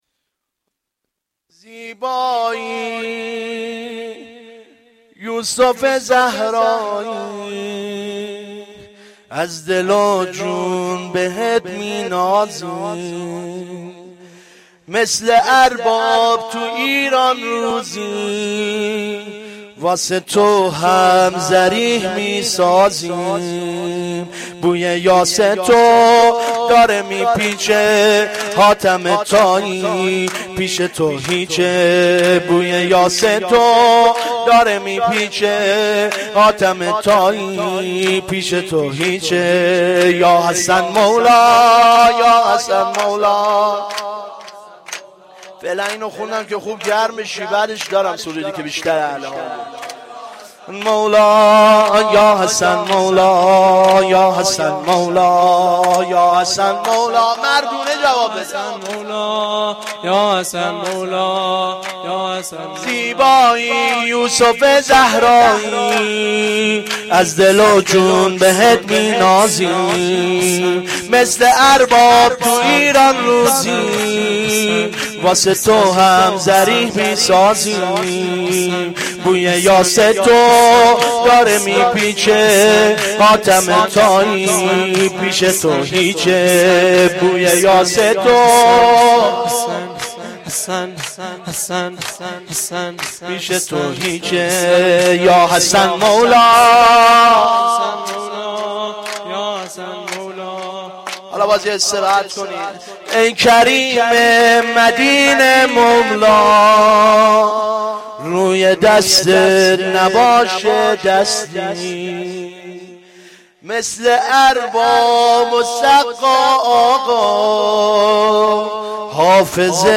شور
shoor1-Rozatol-Abbas.Milad-Emam-Hasan.mp3